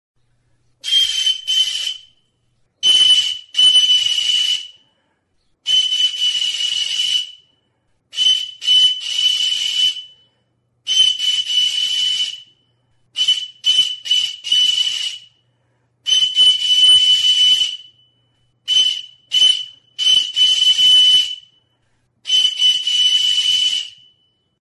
Aerophones -> Flutes -> Fipple flutes (one-handed)
Recorded with this music instrument.
Haur hots-jostailua.
Zulorik gabeko txulubita da.
METAL; TIN